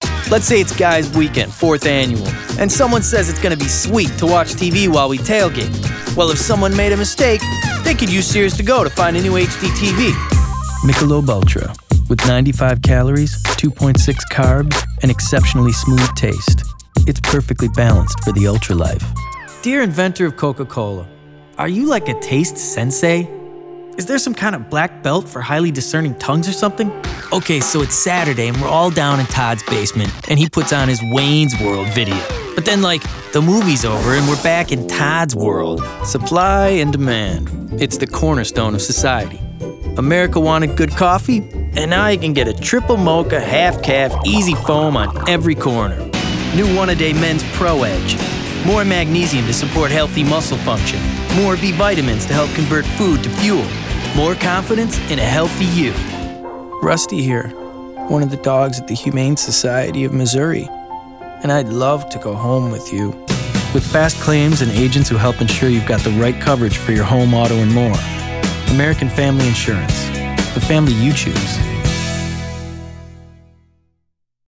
Voiceover : Commercial : Men